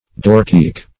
Search Result for " doorcheek" : The Collaborative International Dictionary of English v.0.48: Doorcheek \Door"cheek`\, n. The jamb or sidepiece of a door.